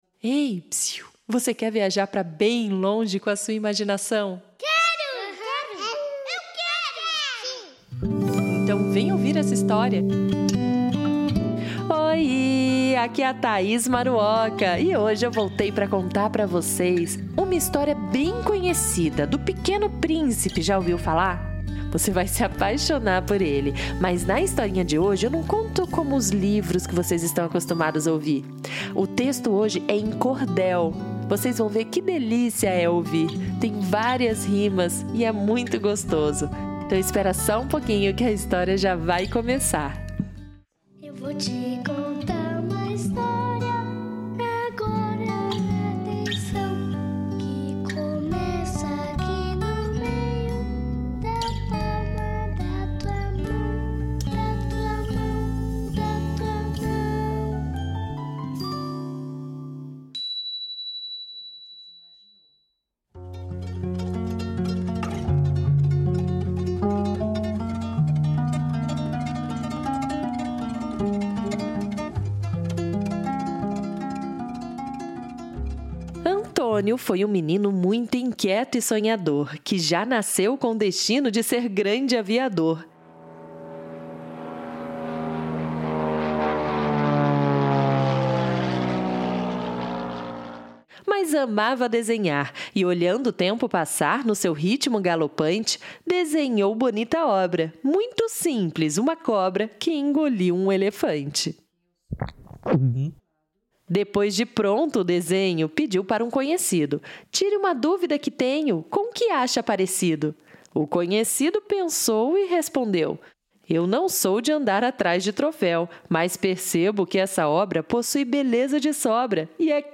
O livro conta a história do famoso Pequeno Príncipe, com versos e rimas, trazendo a cultura popular brasileira, com uma linguagem informal.